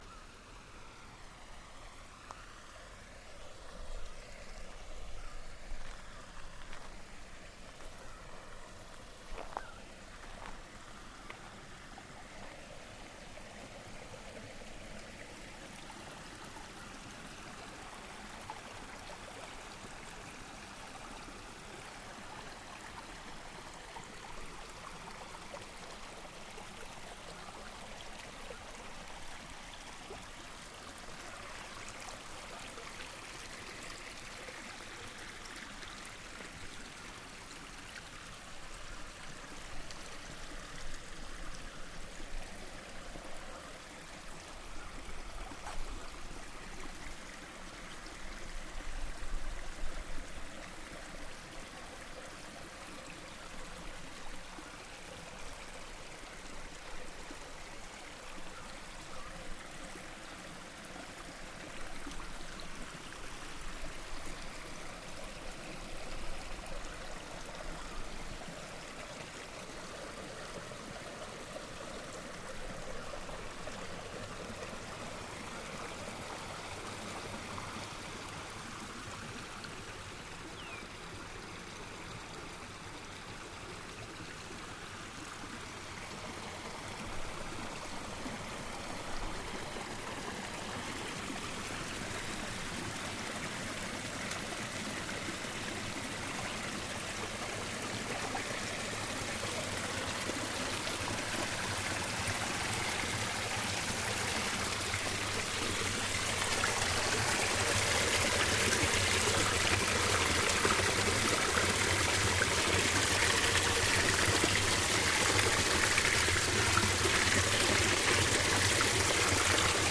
Knuckle Ranges river soundwalk, Sri Lanka